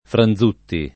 Franzutti [ fran z2 tti ] cogn.